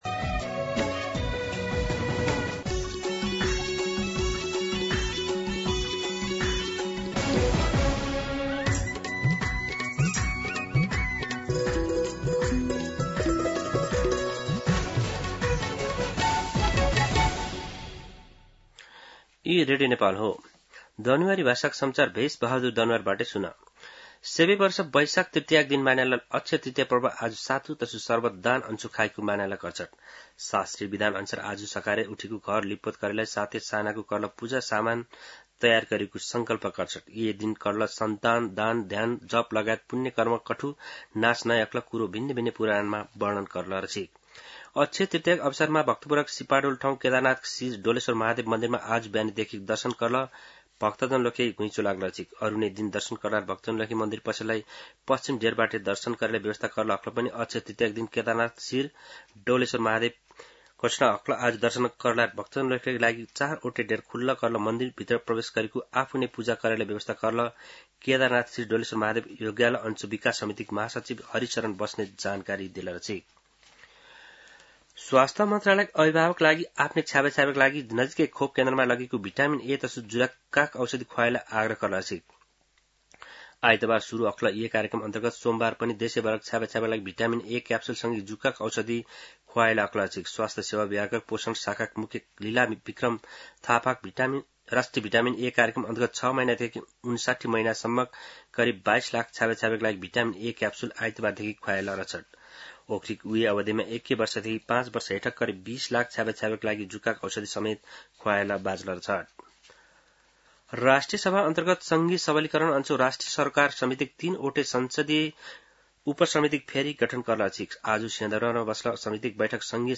दनुवार भाषामा समाचार : ७ वैशाख , २०८३
Danuwar-News-07.mp3